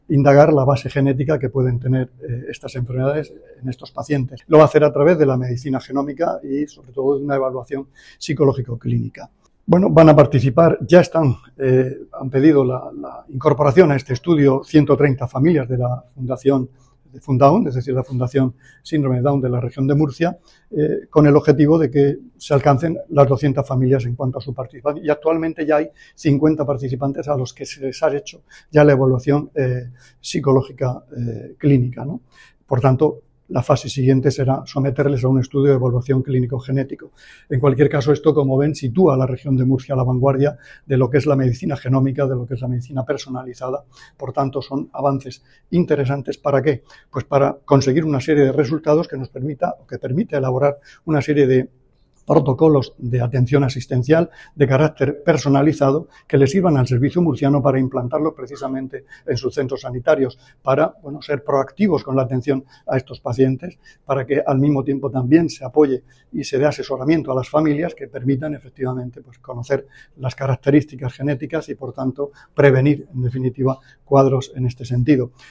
Declaraciones del consejero de Salud, Juan José Pedreño, sobre el estudio para identificar las causas de la discapacidad intelectual en adultos.